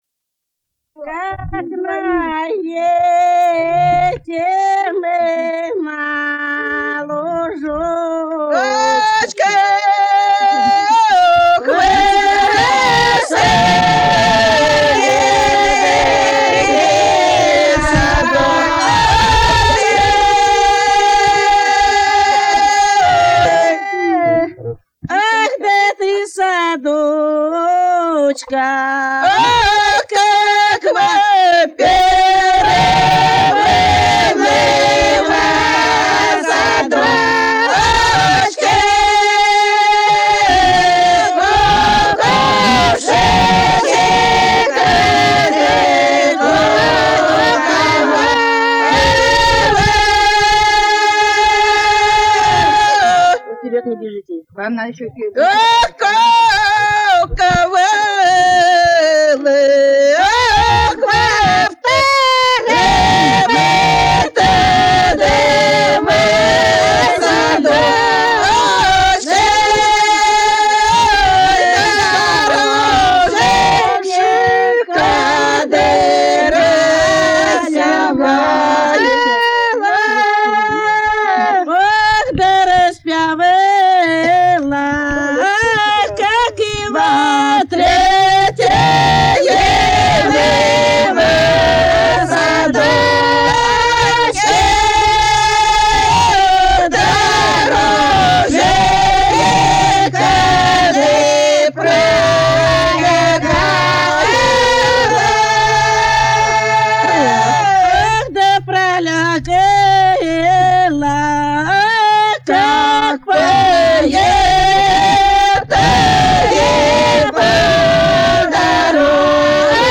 Народные песни Касимовского района Рязанской области «Как на етим на лужочке», протяжная.